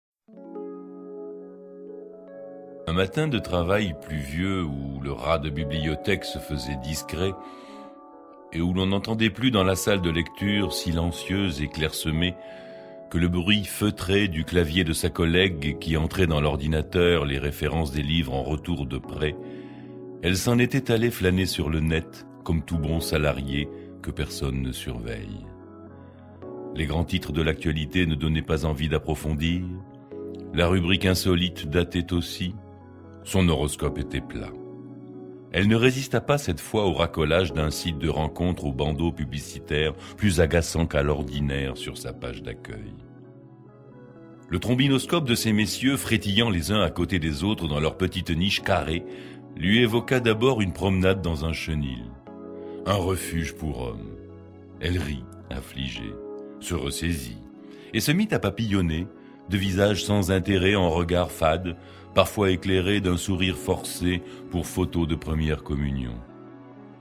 Ainsi, la difficulté de se rencontrer est au coeur de ce roman qui met au premier plan la quête de l’autre comme ultime résistance à l’ultra modernité. Un récit tendre et surprenant servi par des acteurs brillants et une musique savamment orchestrée